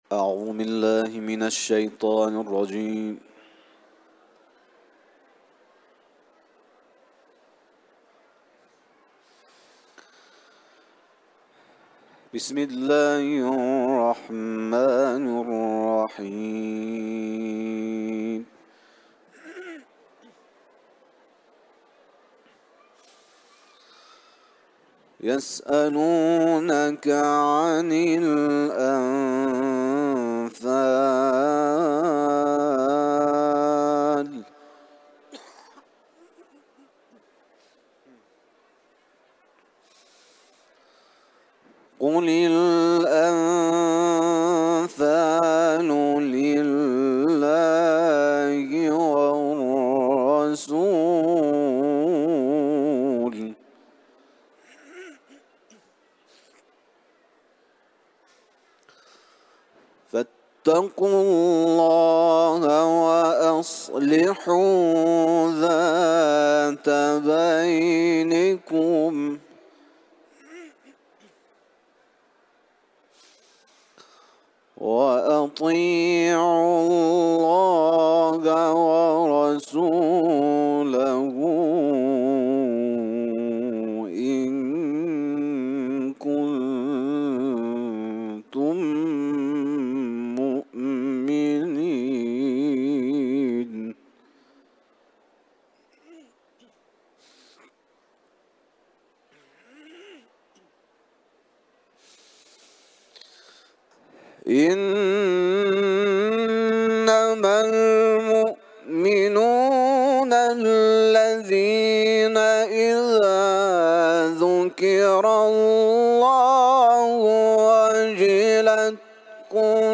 Etiketler: İranlı kâri ، Enfal suresi ، Kuran tilaveti